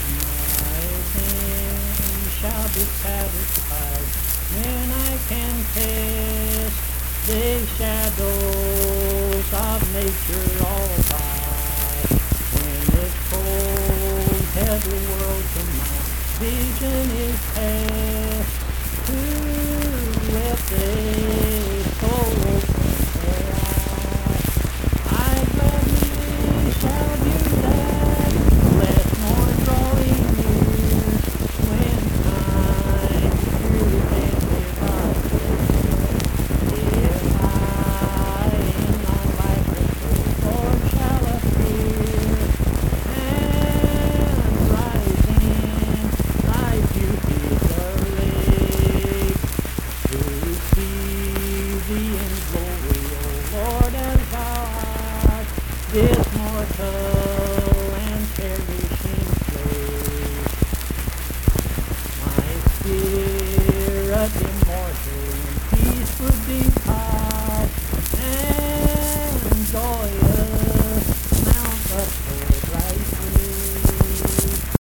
Accompanied (guitar) and unaccompanied vocal music
Verse-refrain 2(12). Performed in Mount Harmony, Marion County, WV.
Hymns and Spiritual Music
Voice (sung)